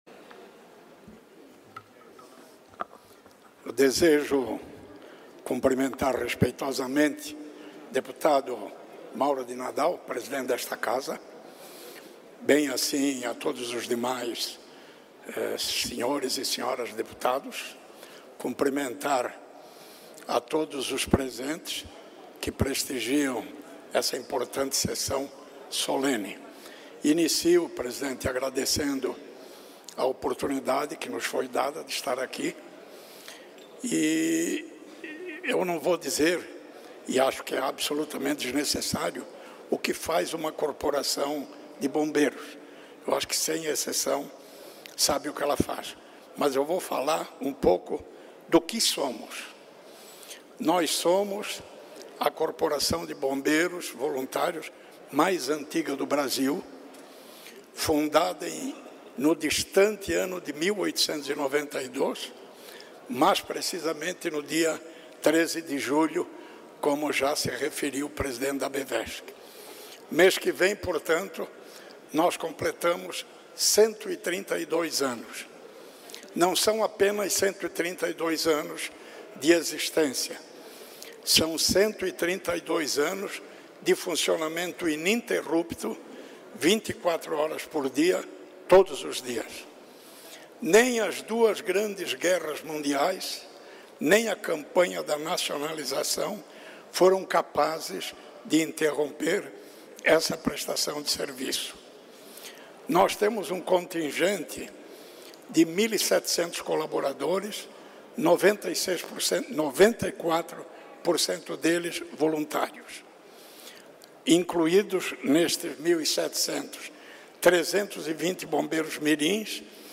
Pronunciamentos das entidades da região Norte na sessão ordinária desta terça-feira (4)